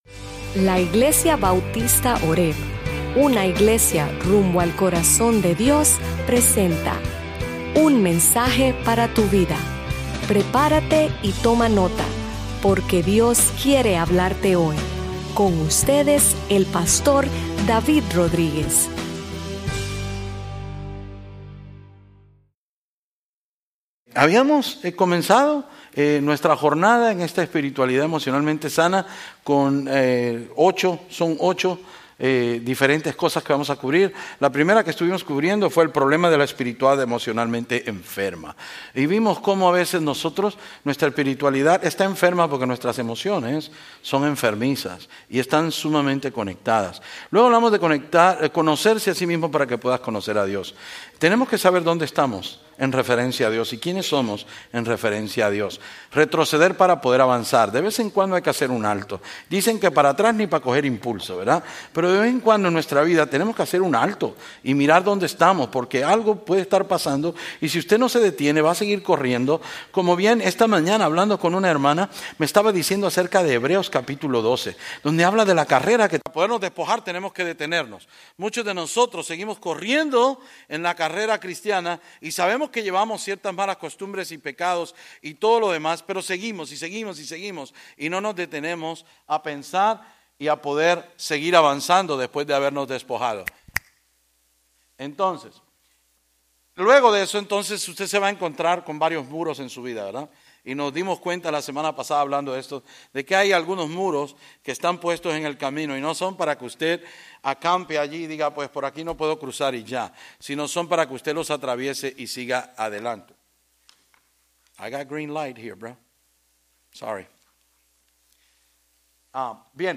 Sermons Archive - Page 117 of 156 - horebnola-New Orleans, LA